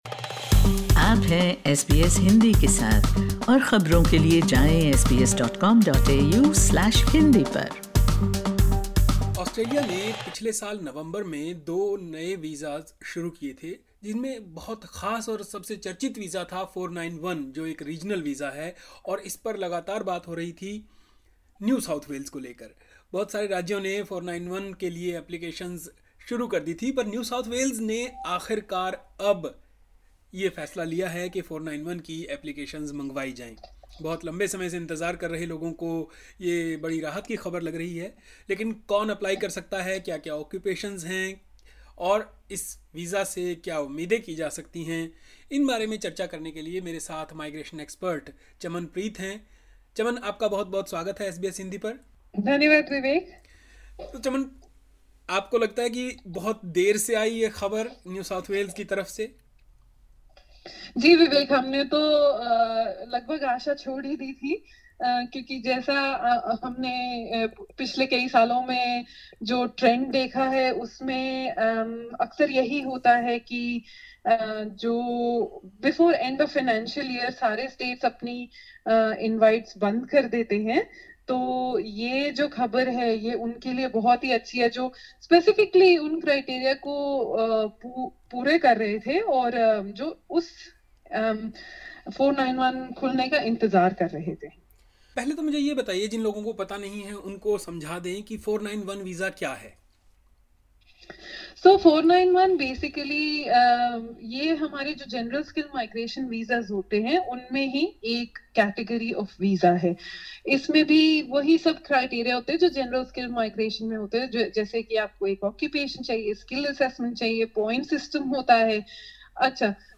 How to apply for the 491 visa? Listen to this interview: LISTEN TO NSW opens 491 visa for over 330 occupations SBS Hindi 10:14 Hindi Who can apply?